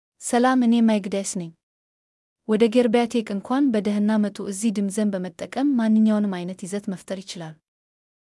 Mekdes — Female Amharic (Ethiopia) AI Voice | TTS, Voice Cloning & Video | Verbatik AI
Mekdes is a female AI voice for Amharic (Ethiopia).
Voice sample
Listen to Mekdes's female Amharic voice.
Female
Mekdes delivers clear pronunciation with authentic Ethiopia Amharic intonation, making your content sound professionally produced.